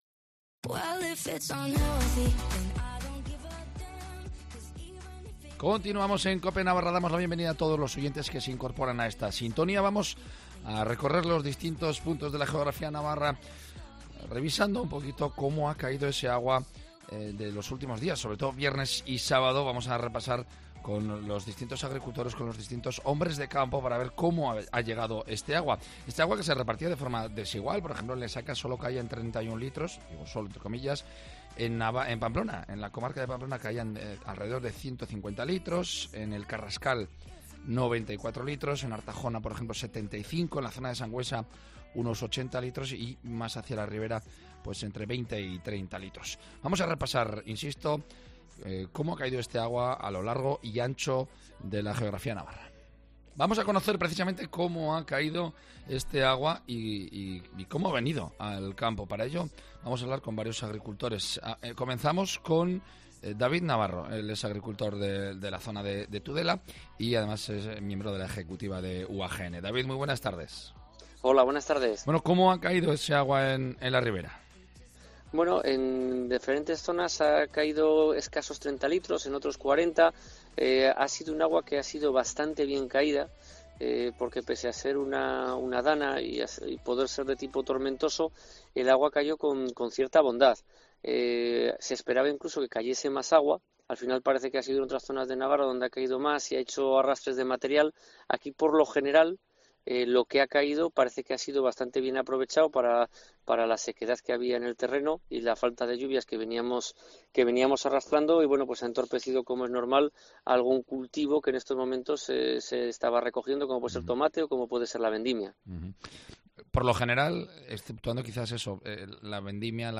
Cuatro ganaderos de distintas zonas de Navarra analizan cómo ha recibido el campo estas lluvias torrenciales
Los agricultores navarros hablan de la DANA